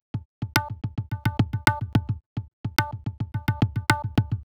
IND. TABLA-L.wav